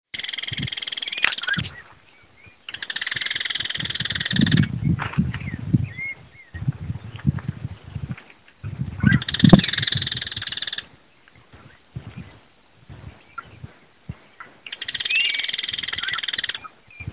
Yellow-chinned Spinetail (Certhiaxis cinnamomeus)
Dos ejemplares al borde de un juncal
Location or protected area: Ceibas
Condition: Wild
Certainty: Observed, Recorded vocal